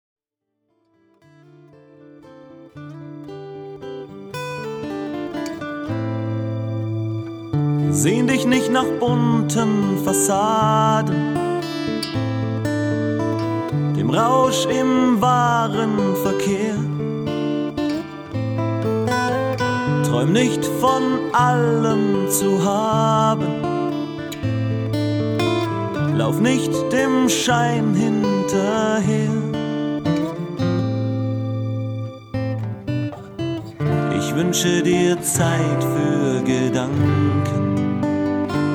• Livemitschnitte 1999-2001
Neutraubling, Bürgerfest 2000 (4:50)